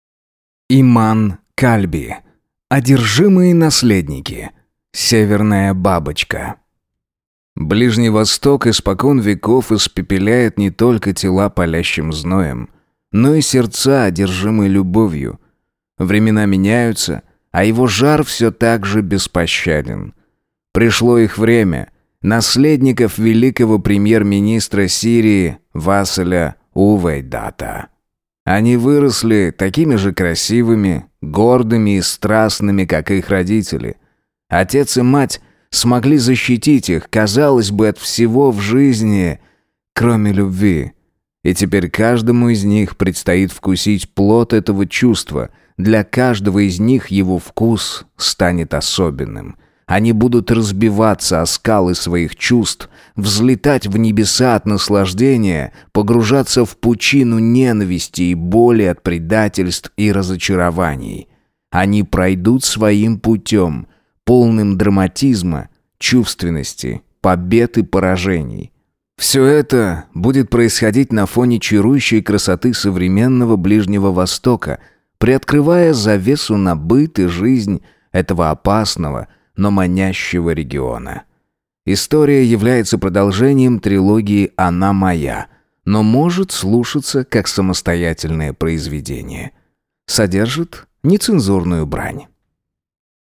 Аудиокнига Одержимые наследники. Северная бабочка | Библиотека аудиокниг